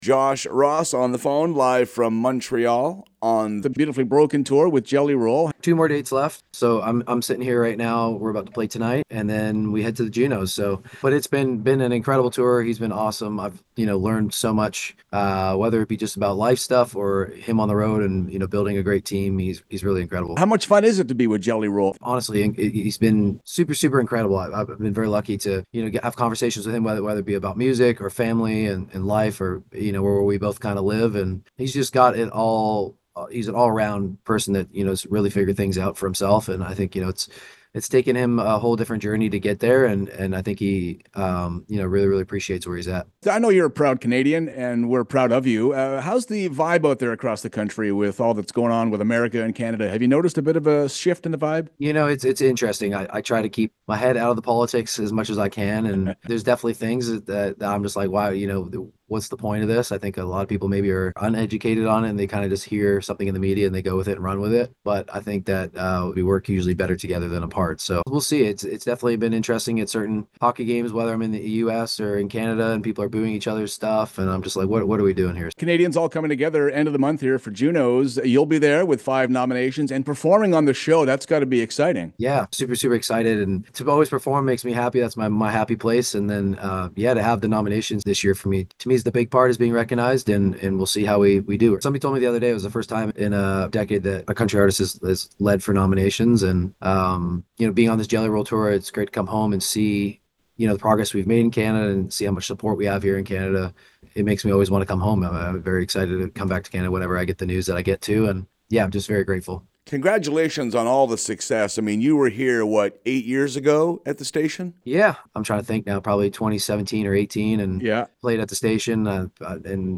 Tune in every Friday morning for weekly interviews, performances, everything LIVE!
josh-ross-interview-2025.mp3